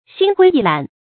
心灰意懒发音
成语注音 ㄒㄧㄣ ㄏㄨㄟ ㄧˋ ㄌㄢˇ